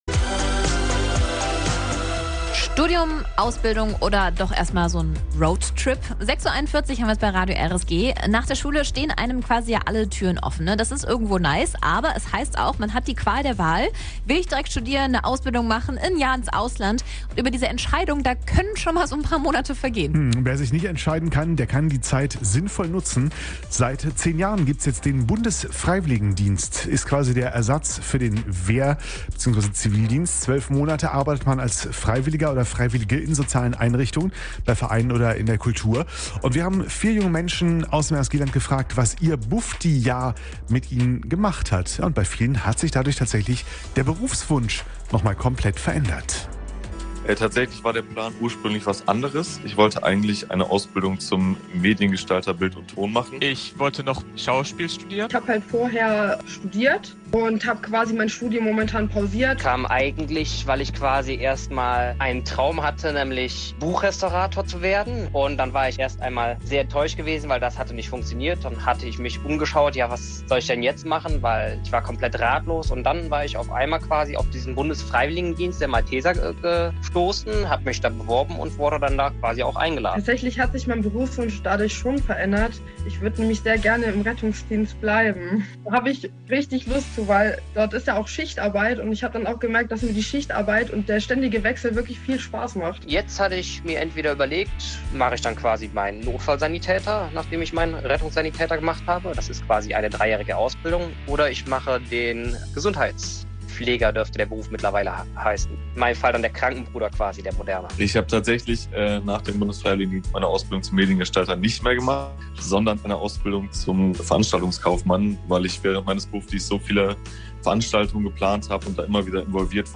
Wir haben junge Menschen aus dem RSG-Land nach ihren Erfahrungen in ihrer Zeit als "Bufdi" gefragt. Hier findet ihr die Interviews zum Nachhören.